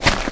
Footstep4.wav